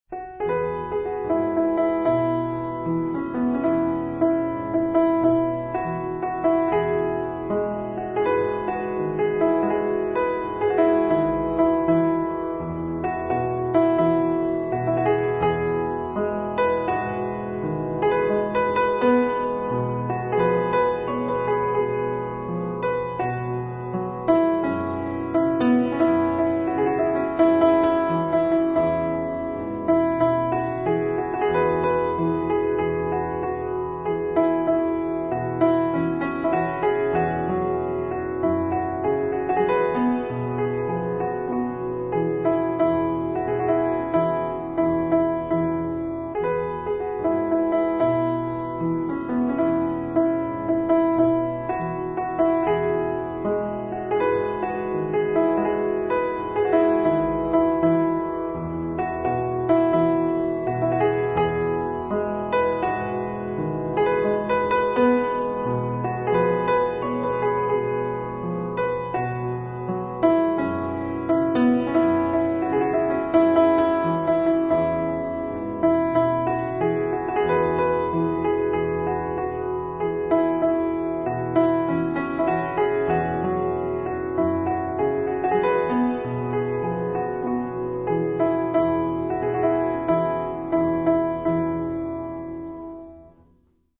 piano.mp3